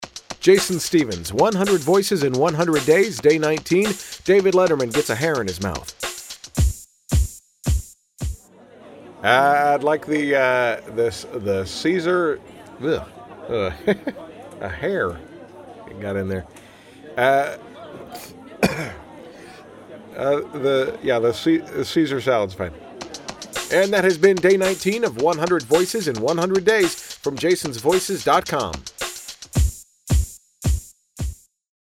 Tags: Celebrity impression voice overs, David Letterman impression